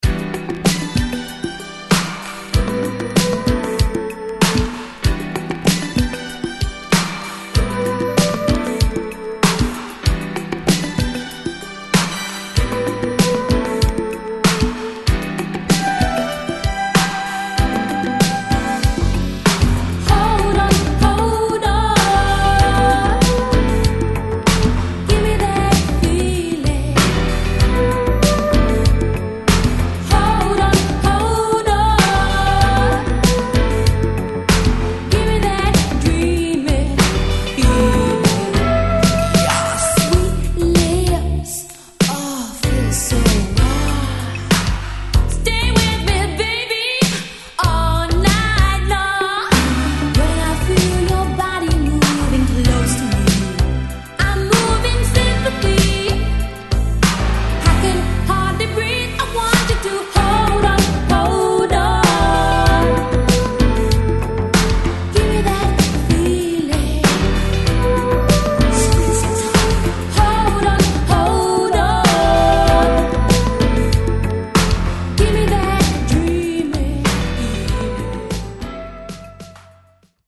Nice Mellow Boogie!!